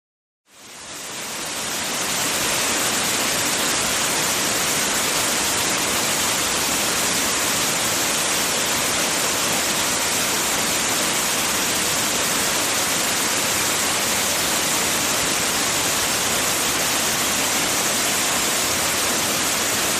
Waterfall
Waterfall is a free nature sound effect available for download in MP3 format.
288_waterfall.mp3